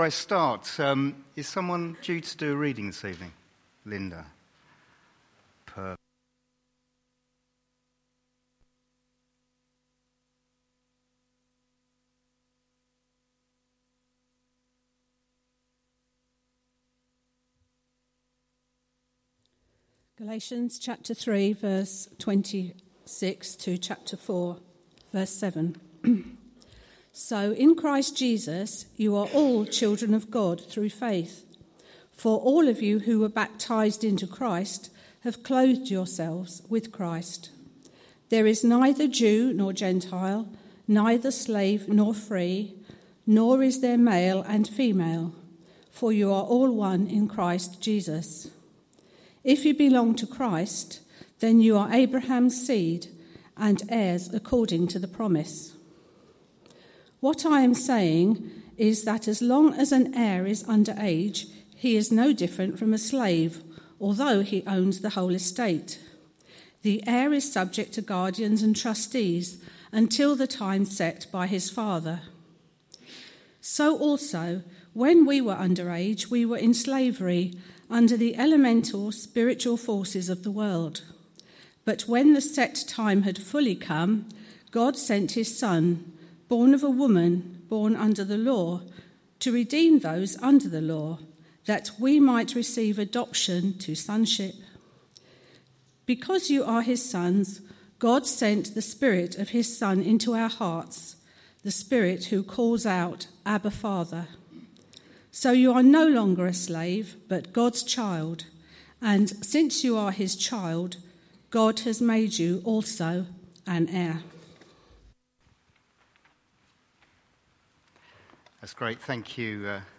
A message from the series "All is Grace."